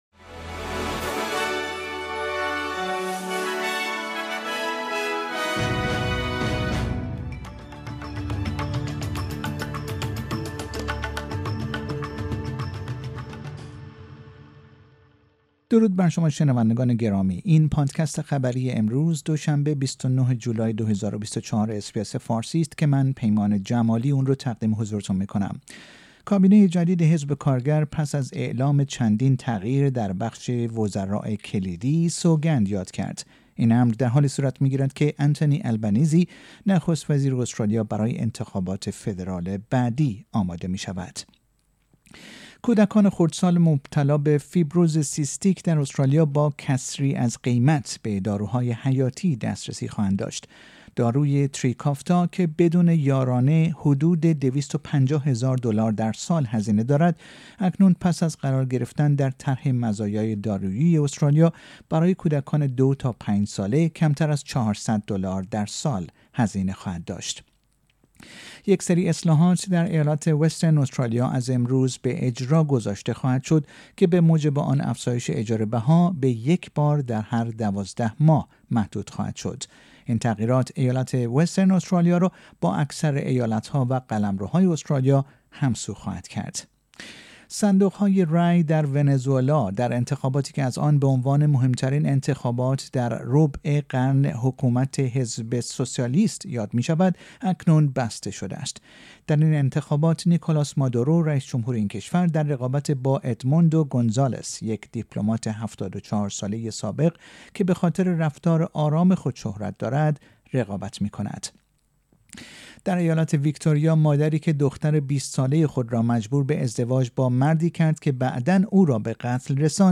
در این پادکست خبری مهمترین اخبار استرالیا در روز دوشنبه ۲۹ جولای ۲۰۲۴ ارائه شده است.